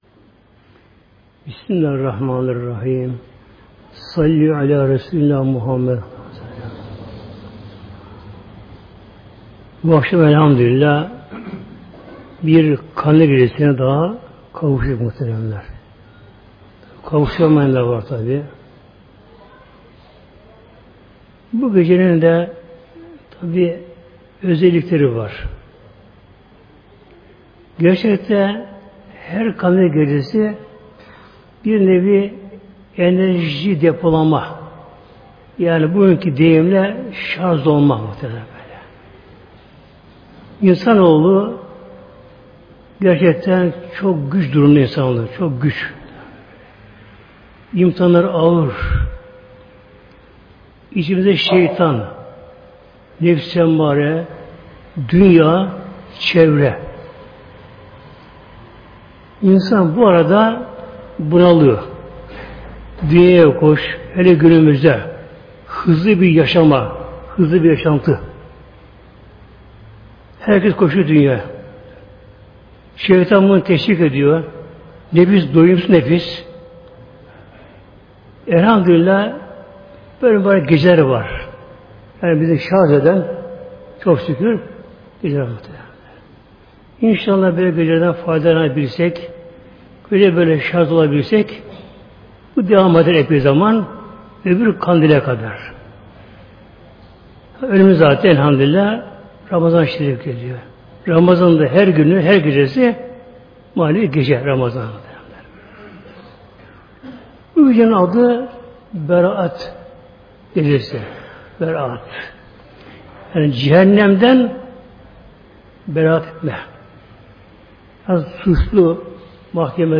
760-Berat-Kandili-Sohbeti.mp3